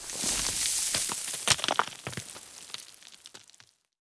expl_debris_sand_02.ogg